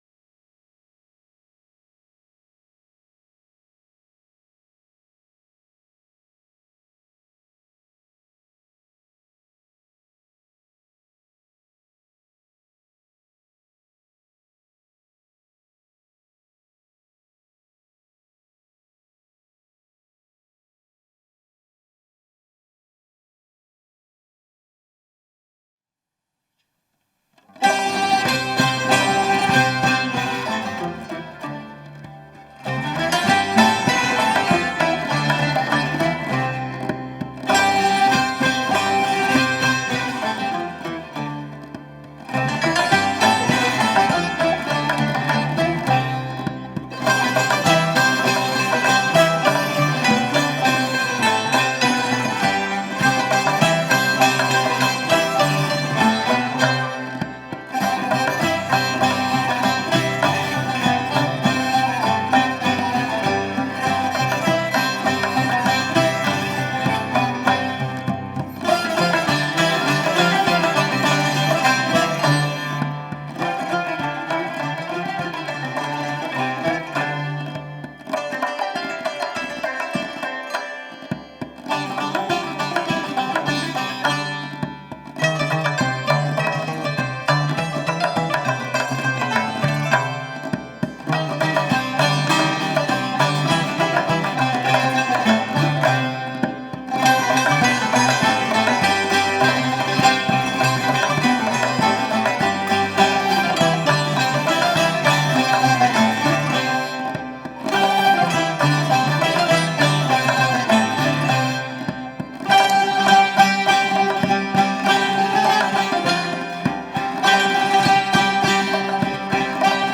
سنتور
قانون